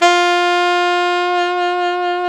Index of /90_sSampleCDs/Roland L-CDX-03 Disk 1/SAX_Alto 414/SAX_Alto mf 414
SAX ALTOMF09.wav